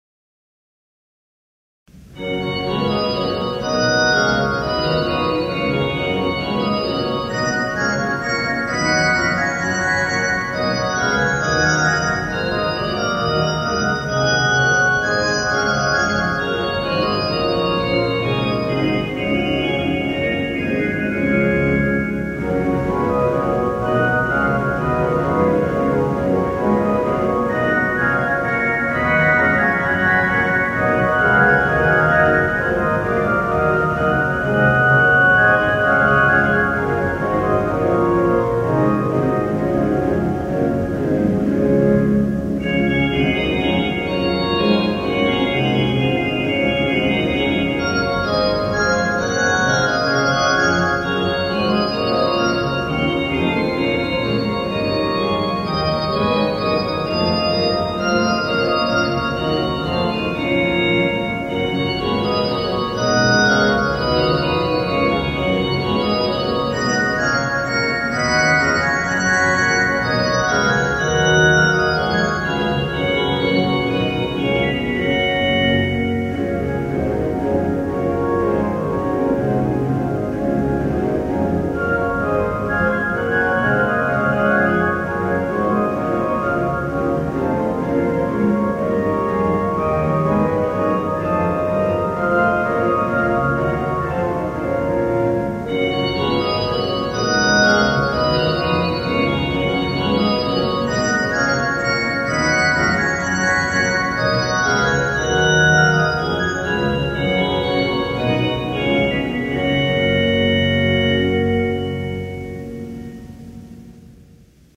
Scherzo by Percy Whitlock  —  1 minute 54 seconds